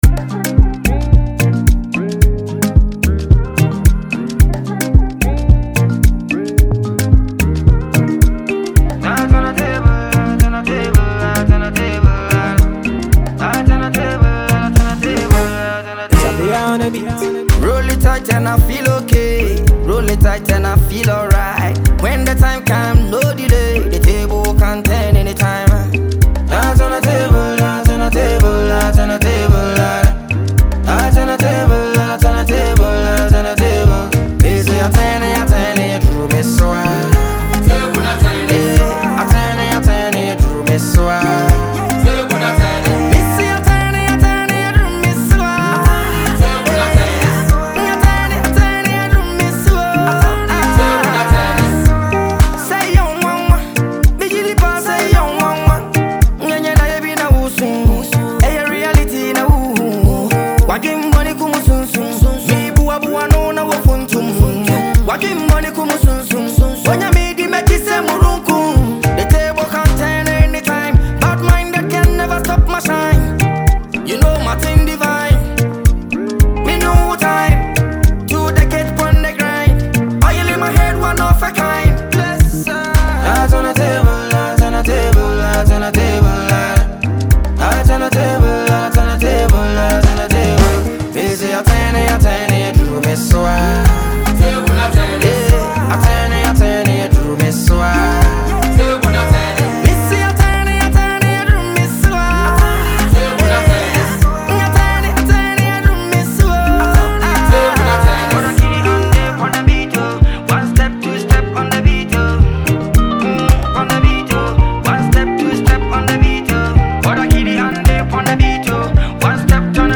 Ghanaian highlife and Afro-fusion
a soulful and captivating tune
With emotionally rich lyrics and a sweet highlife groove